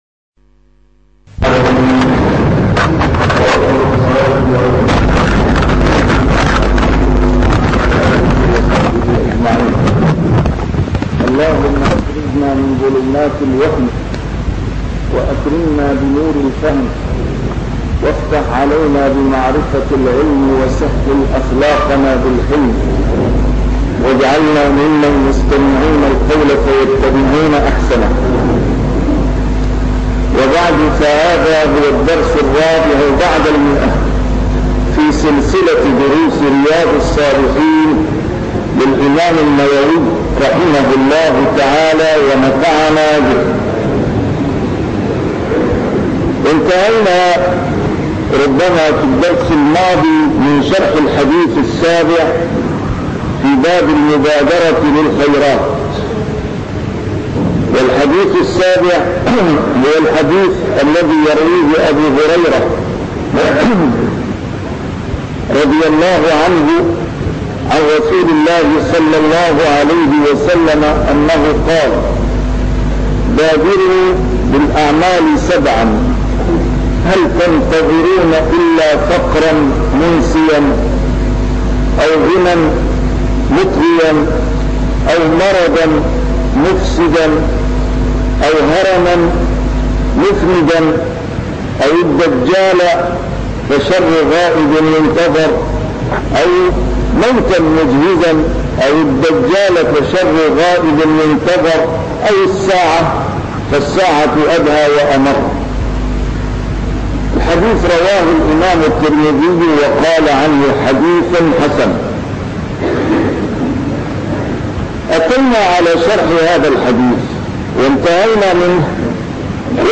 A MARTYR SCHOLAR: IMAM MUHAMMAD SAEED RAMADAN AL-BOUTI - الدروس العلمية - شرح كتاب رياض الصالحين - 104- شرح رياض الصالحين: المبادرة إلى الخيرات